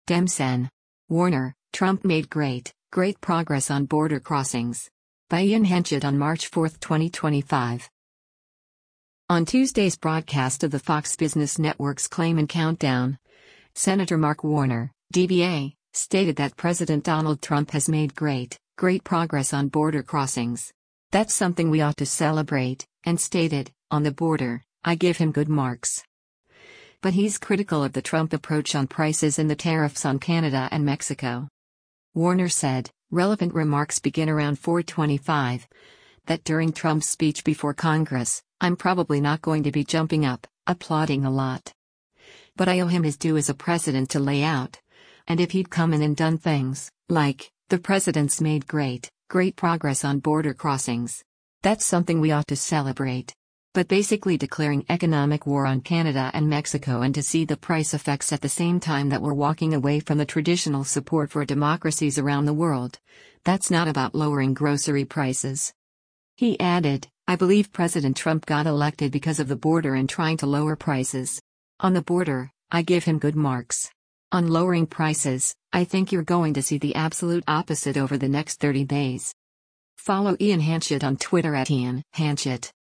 On Tuesday’s broadcast of the Fox Business Network’s “Claman Countdown,” Sen. Mark Warner (D-VA) stated that President Donald Trump has “made great, great progress on border crossings. That’s something we ought to celebrate.”